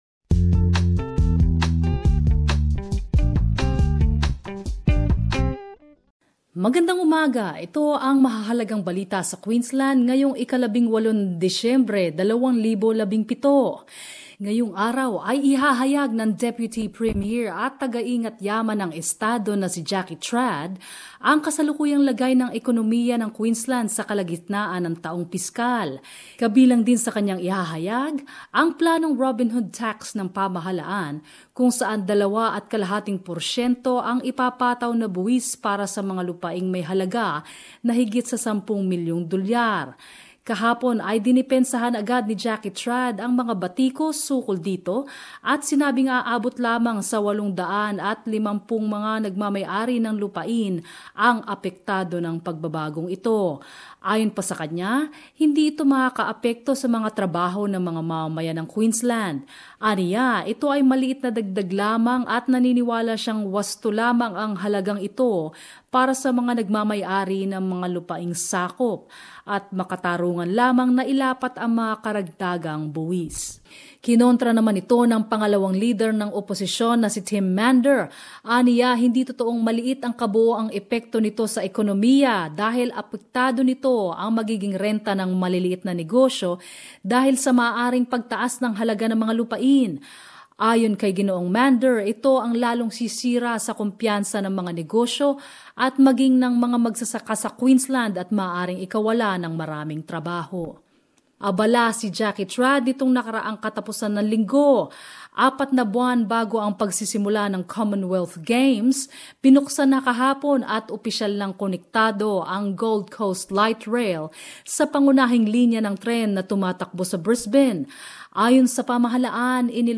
Queensland News.